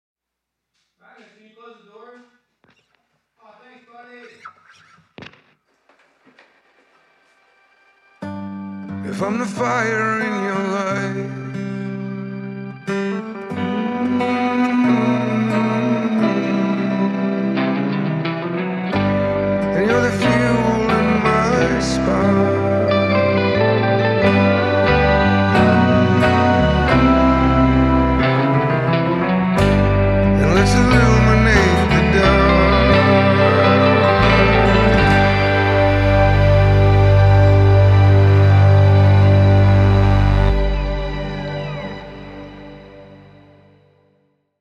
a lo-fi, meditative introductory track
in an uncharacteristically hushed, almost prayer-like tone
Vocals, Tenor Guitar, Ukulele